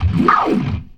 GLISS 1   -L.wav